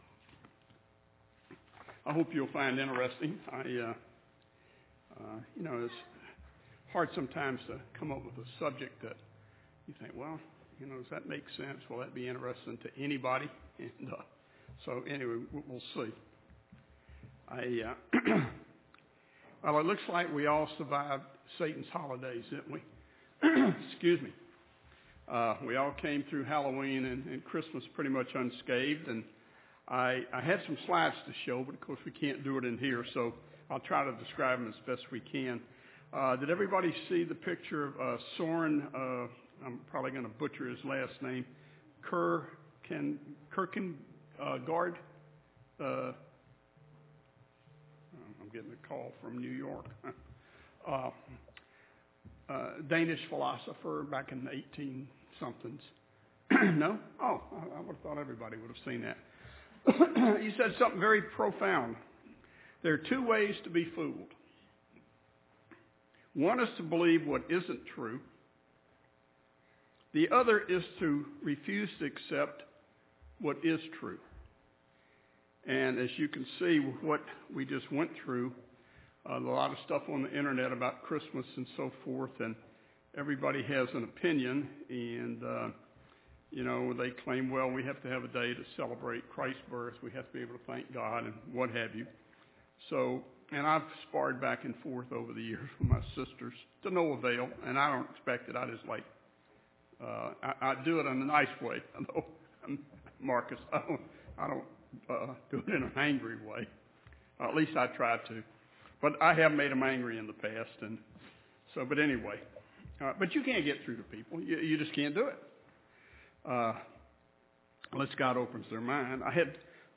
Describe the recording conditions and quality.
Given in Rome, GA